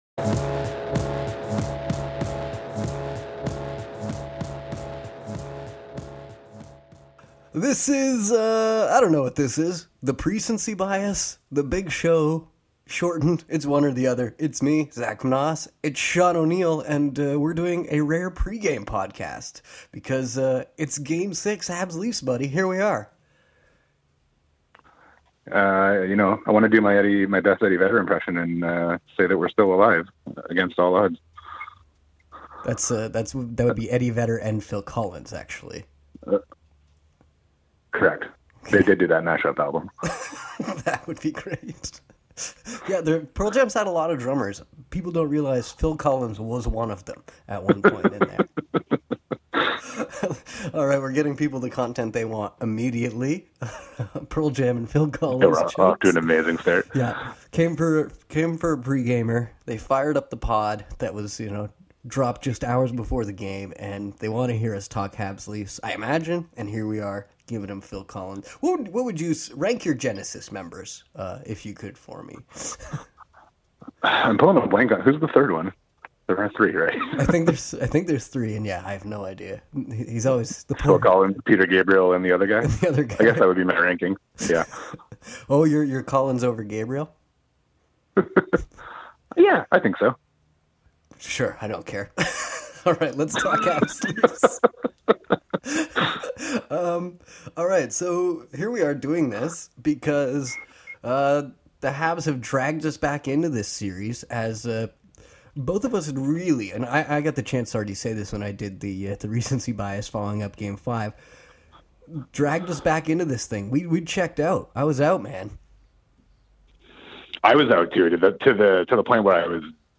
The boys on the Bias jumped on the mics for a quick pre-game pod ahead of the massive Game 6 in Montreal on Saturday night.